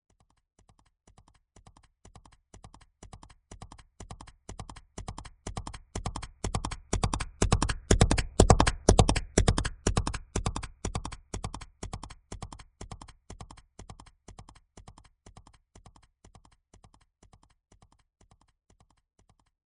右から左へと走り抜ける音。
馬が走り抜ける足音 着信音